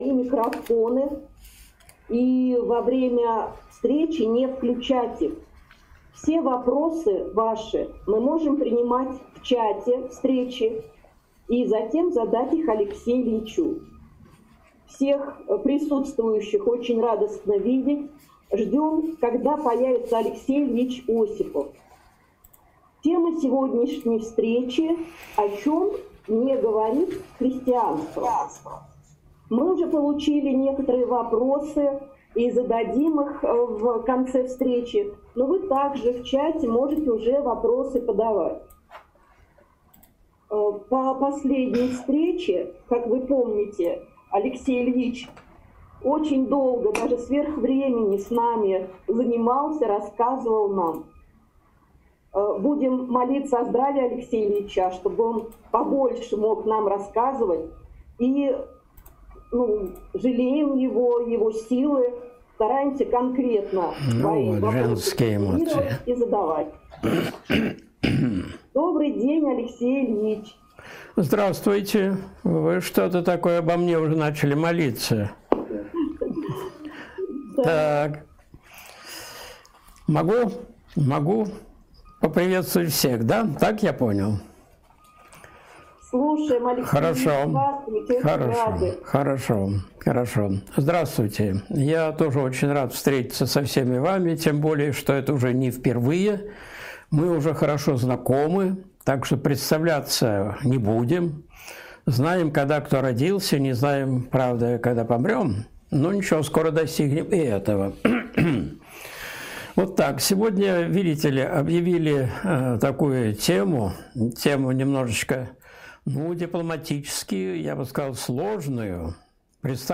О чём сегодня не говорит христианство? (Прямой эфир, 22.11.2020)
Видеолекции протоиерея Алексея Осипова